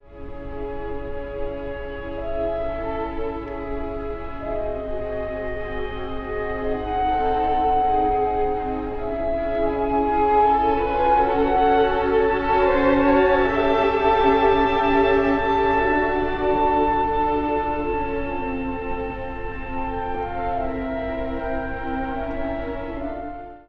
繊細な転調をともないながら、第二主題へ…この旋律がまた美しい！
まるで誰かの心の奥底のつぶやきのように、やさしく、でも深く響いてきます。